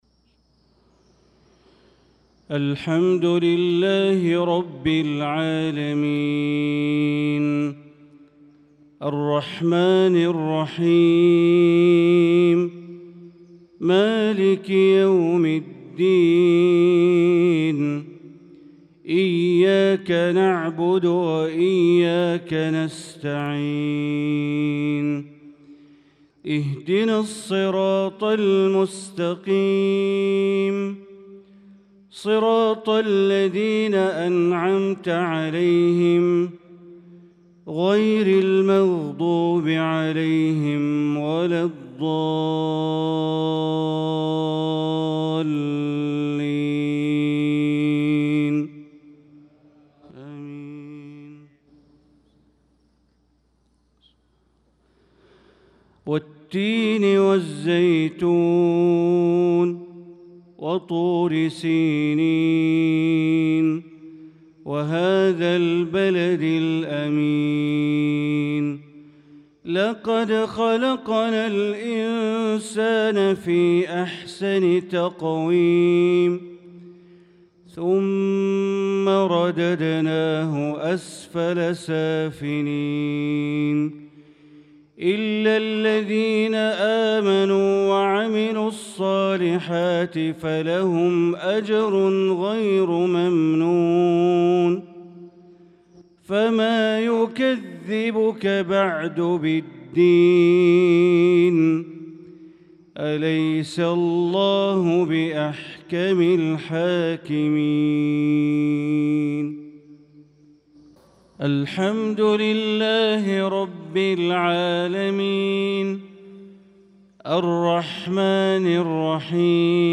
صلاة المغرب للقارئ بندر بليلة 18 شوال 1445 هـ
تِلَاوَات الْحَرَمَيْن .